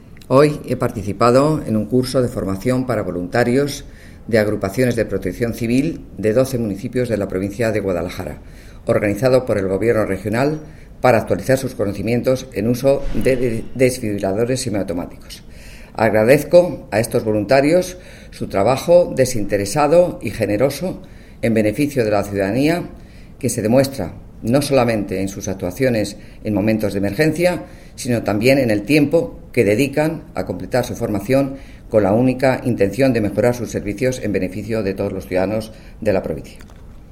La directora provincial de Hacienda y Administraciones Públicas en Guadalajara, Ángela Ambite, habla del curso de desfibriladores automáticos para voluntarios de Protección Civil organizado por el Gobierno regional en la provincia de Guadalajara.